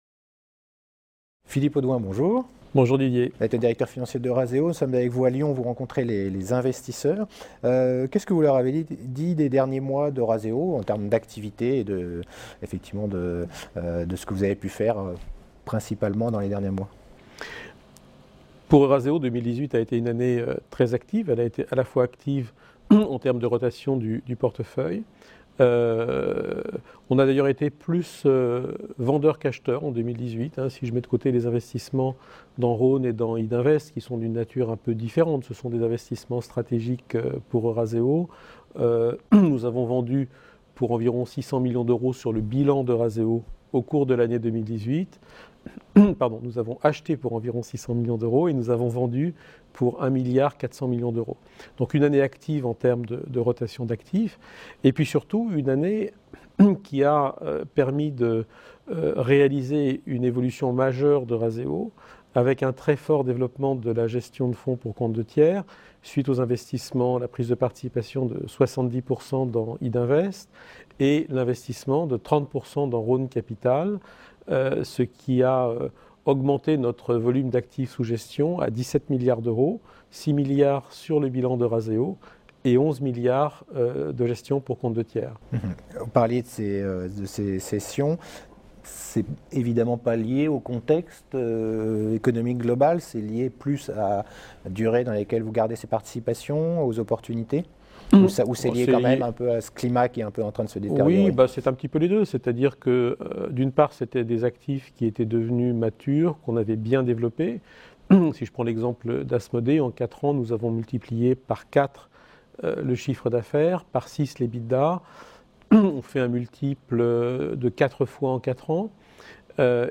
La Web Tv a rencontré les dirigeants au Oddo Forum qui s'est tenu à Lyon le 10 et le 11 janvier